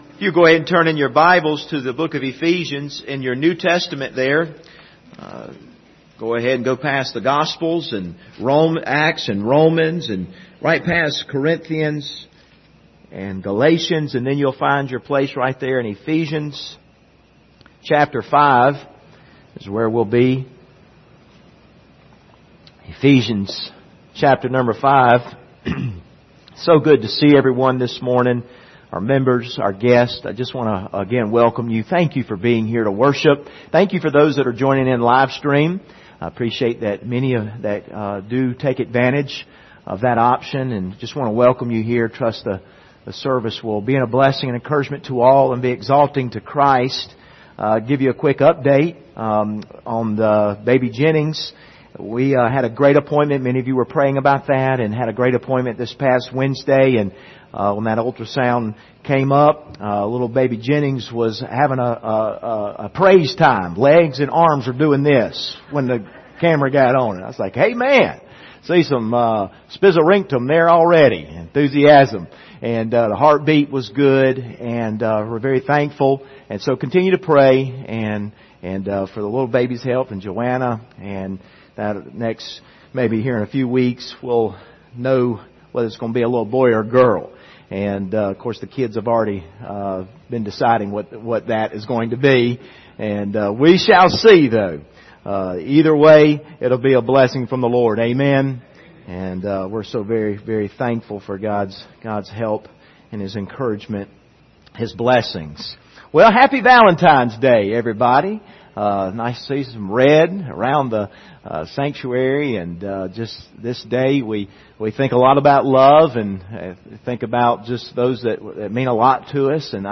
Passage: Ephesians 5:1-7 Service Type: Sunday Morning